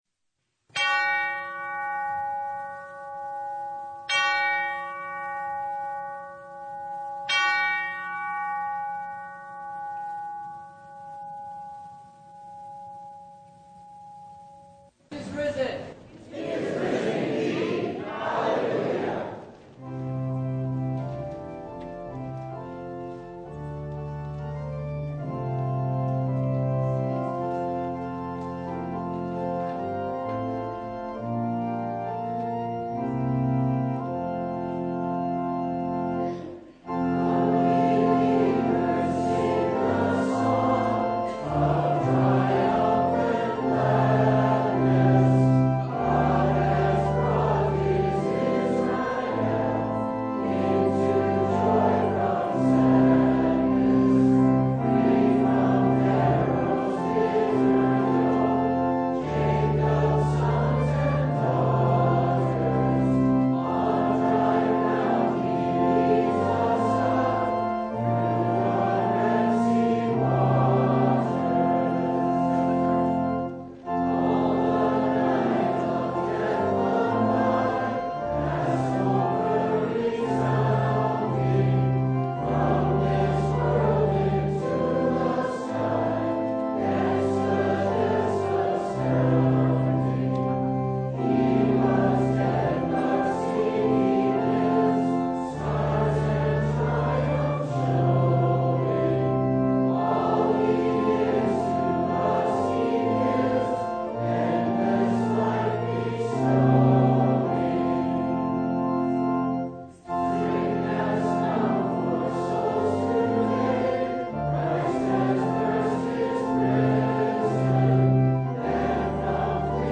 Service of the Word
Full Service